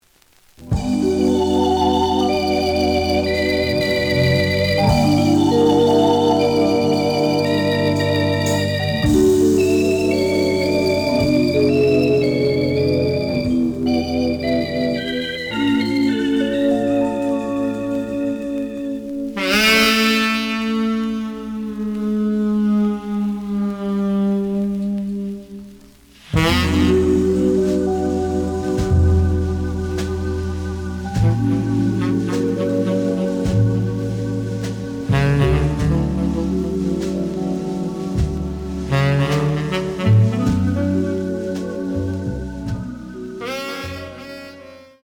The audio sample is recorded from the actual item.
●Genre: Jazz Other
Slight edge warp.